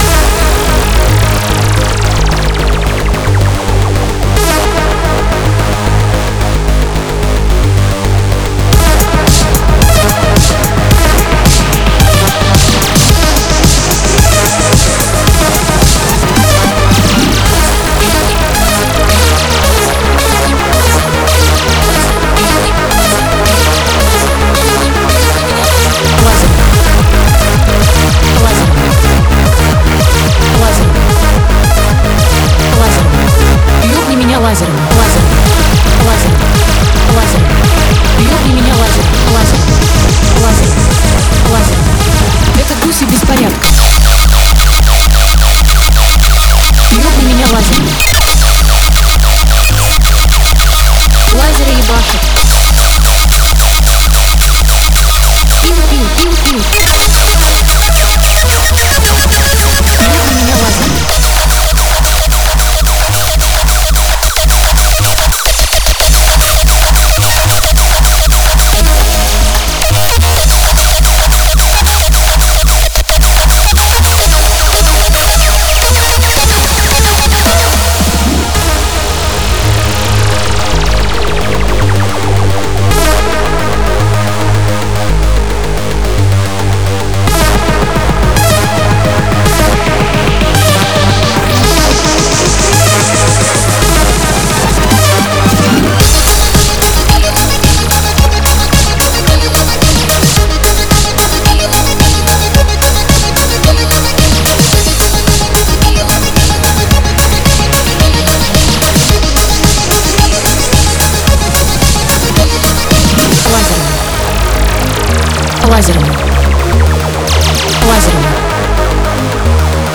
UPTEMPO HARDCORE